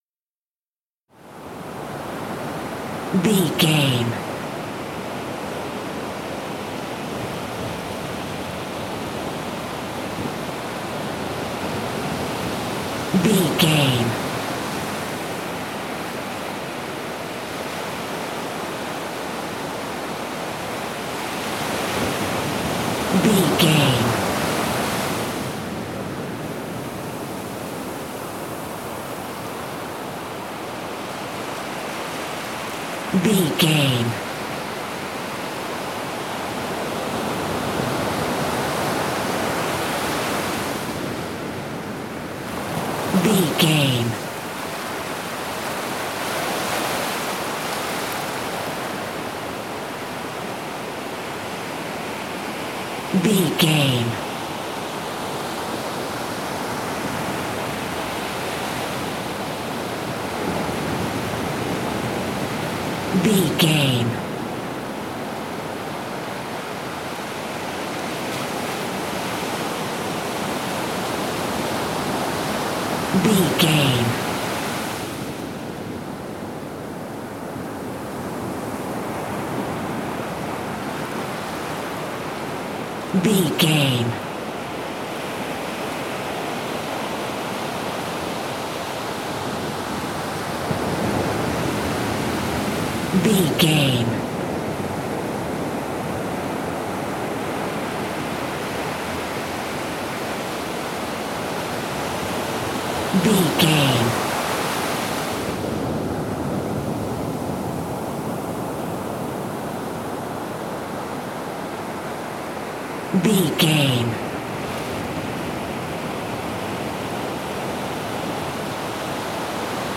Beach ocean waves
Beach ocean waves 3
Sound Effects
calm
nature
peaceful
repetitive
ambience